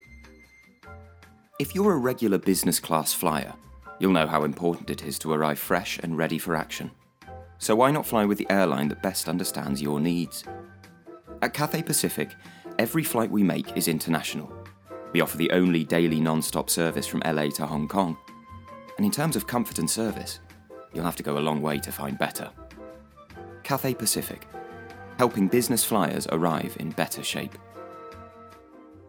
• Male
Showing: Commerical Clips
Cathay Pacific. Natural, Soft, Reliable